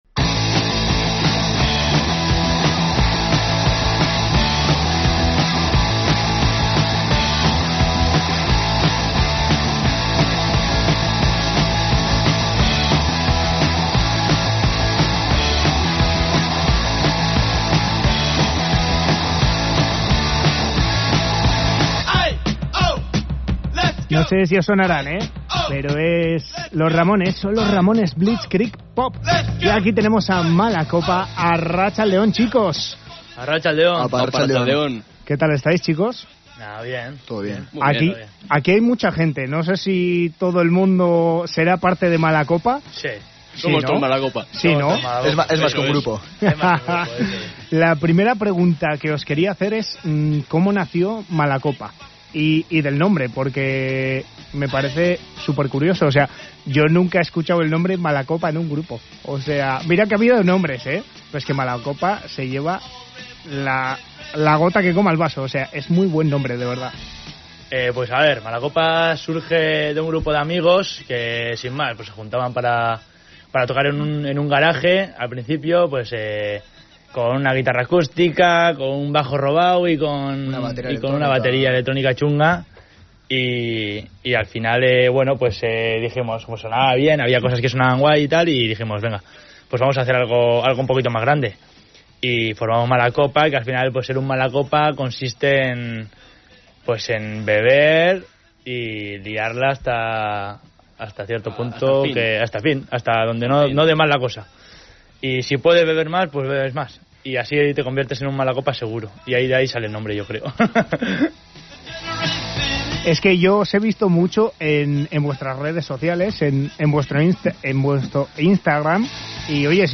Escucha la entrevista con el grupo Malacopa , que tienen mucho que contar y sobre todo que cantar
Entrevista a Malacopa (06/06/2025)